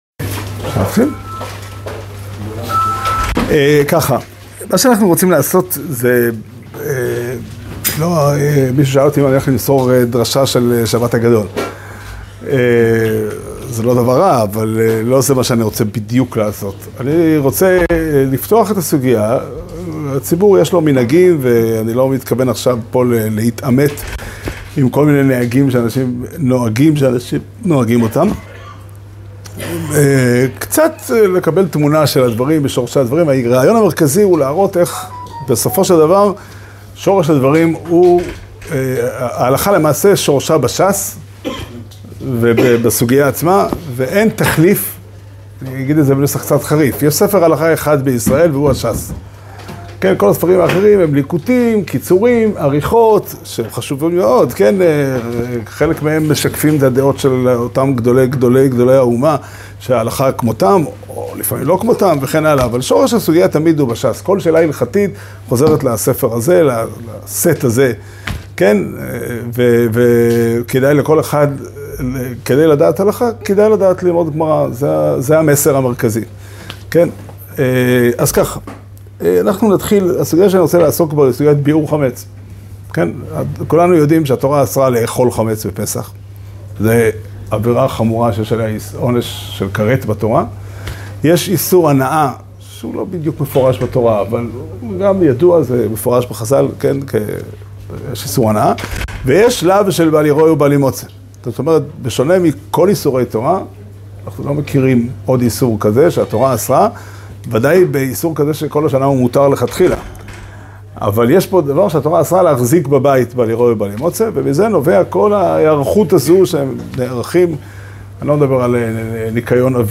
שיעור שנמסר בבית המדרש פתחי עולם בתאריך ג' ניסן תשפ"ד